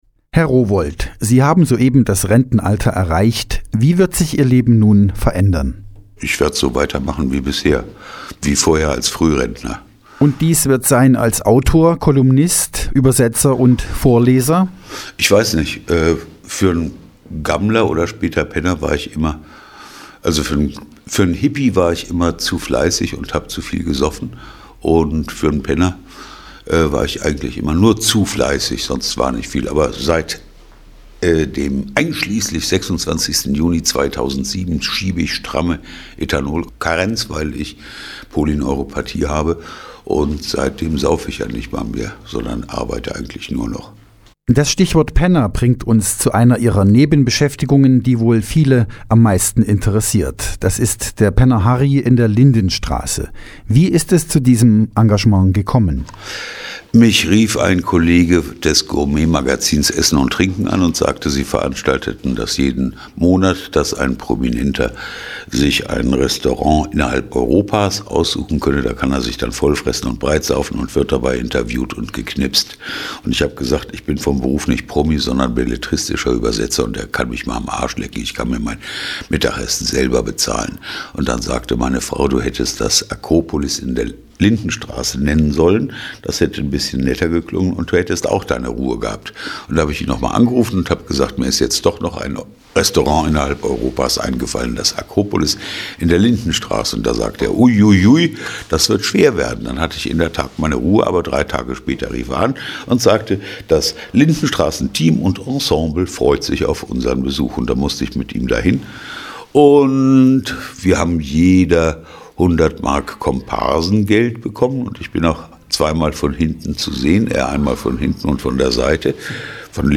Denn bei diesem anläßlich seiner Lesung in Nordhausen interviewten Mann wirkt am meisten die Stimme.